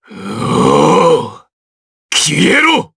DarkKasel-Vox_Skill7_jp.wav